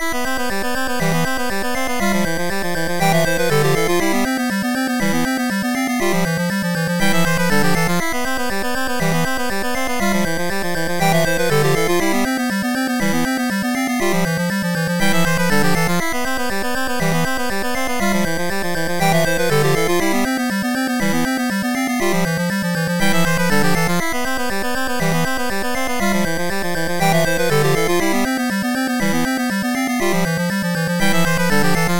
8bit styled loop